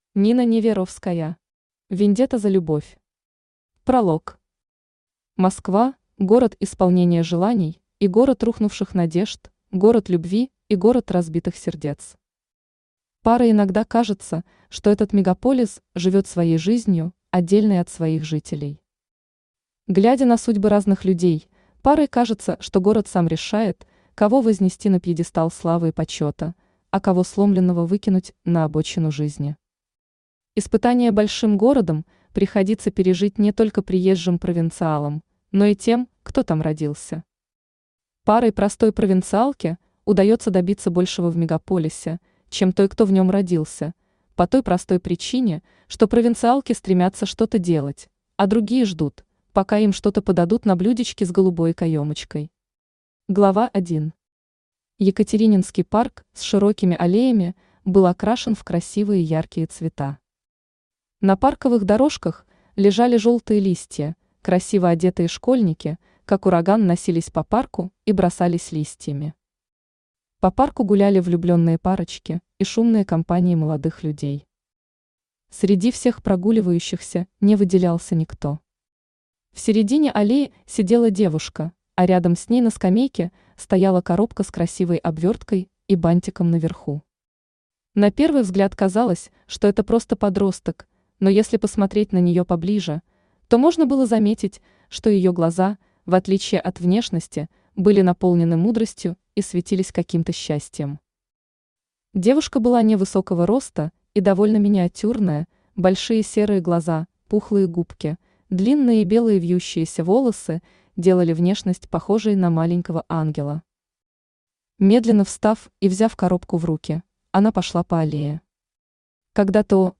Аудиокнига Вендетта за любовь | Библиотека аудиокниг
Aудиокнига Вендетта за любовь Автор Нина Владимировна Неверовская Читает аудиокнигу Авточтец ЛитРес.